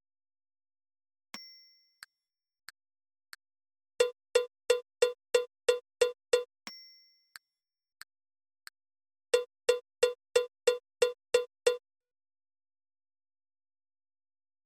「リズムカード」正解のリズム音源
※リズム音源のテンポは♩＝90です。Moderato/モデラート(♩＝76～96)ほどのテンポとなります。
※はじめにカウントが流れます。
※リズムは2回流れます。